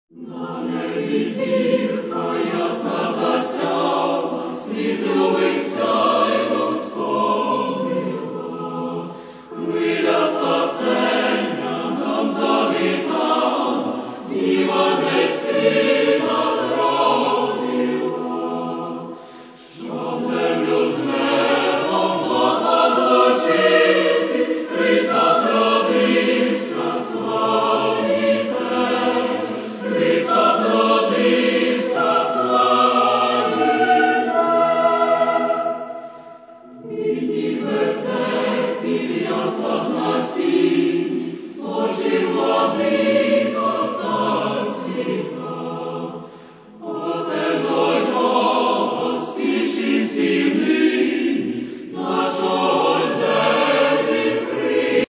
Christmas  (94)